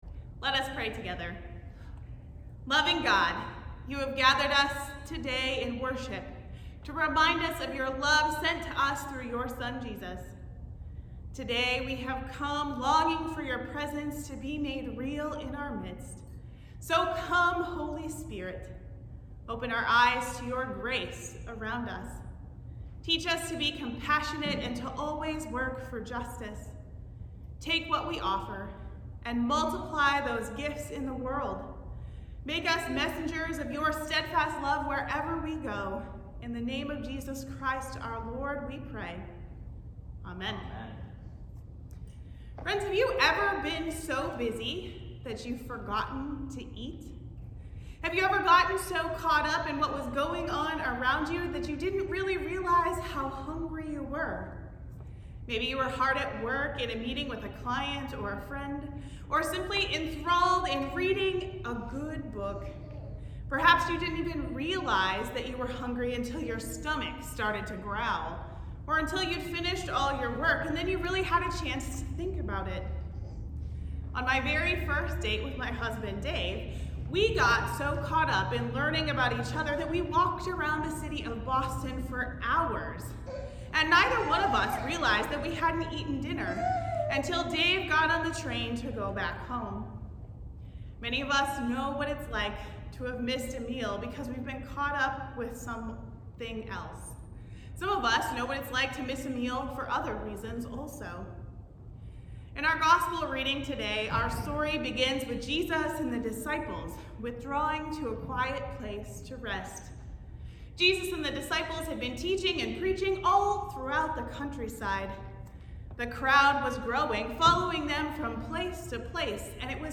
Sermons | Rockport United Methodist Church